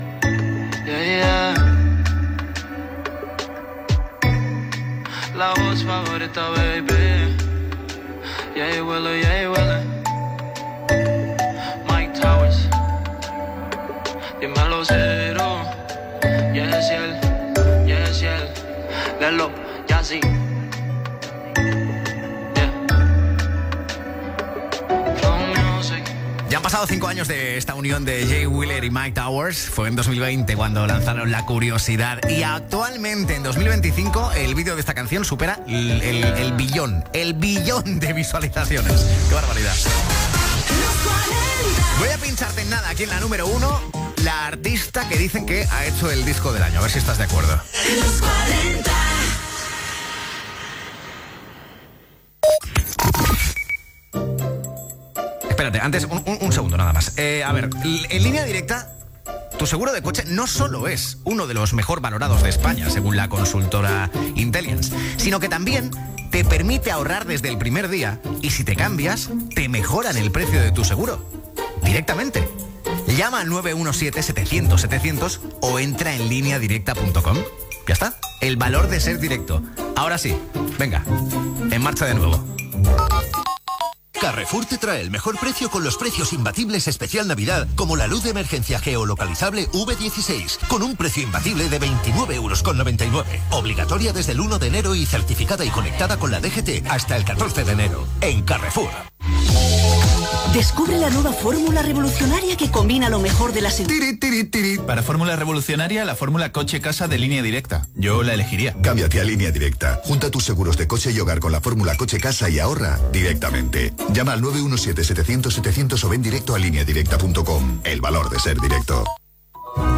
Tema musical, comentari, indicatiu de la ràdio, publicitat, cent anys de la ràdio i els artistes, publicitat
Musical
FM